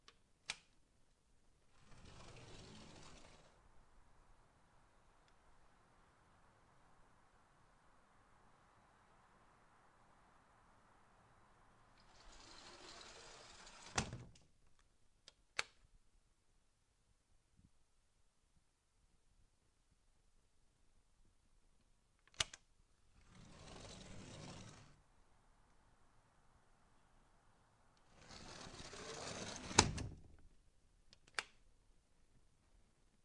描述：当地食品店外的停车场的录音。以立体声录制
Tag: 很多 摩托车 停车